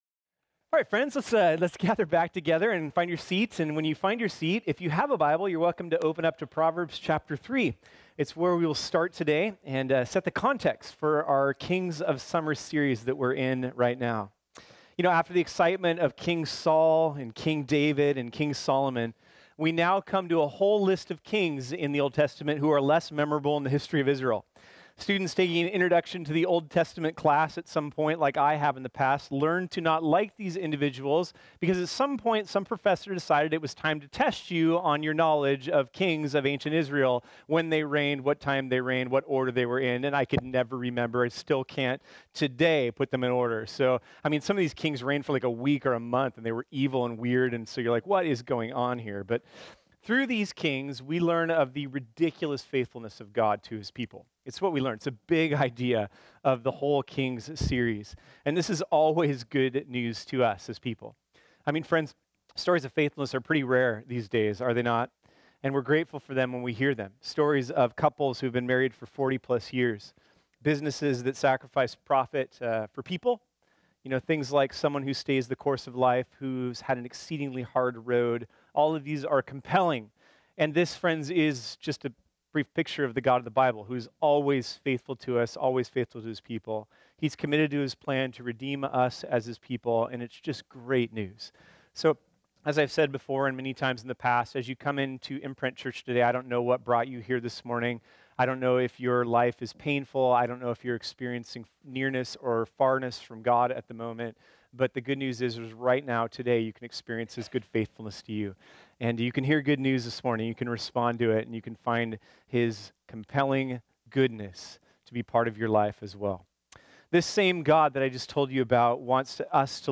This sermon was originally preached on Sunday, July 1, 2018.